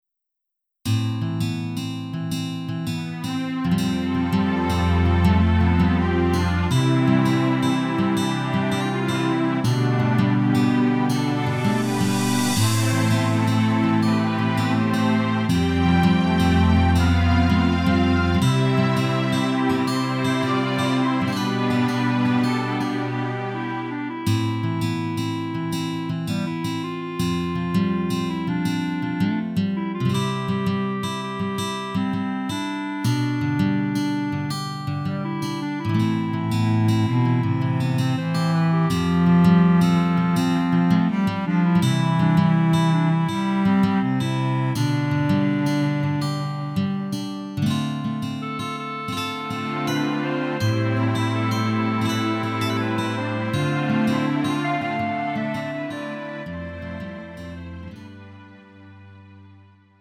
음정 -1키 3:59
장르 가요 구분